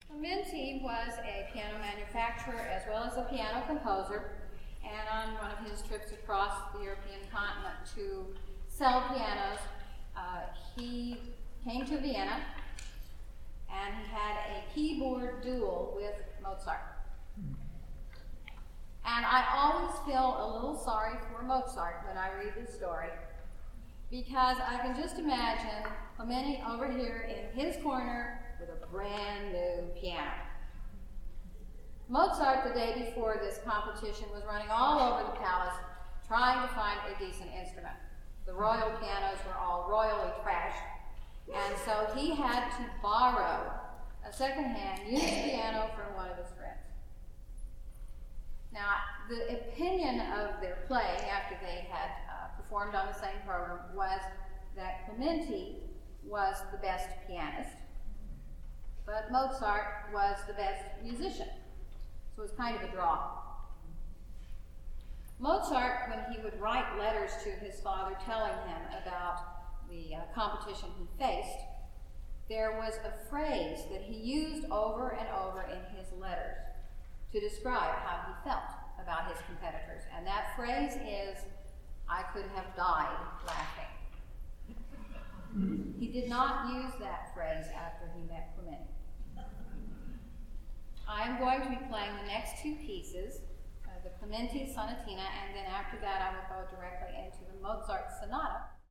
The selections below are from a concert I played called "The Essential Pianist".